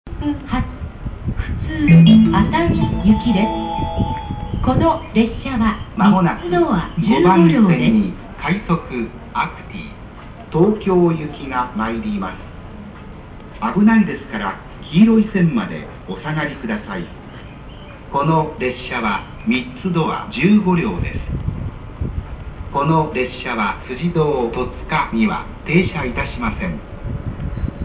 接近放送快速アクティ東京行き快速アクティ東京行きの接近放送です。放送同士が被っている部分がありますが、ご了承ください。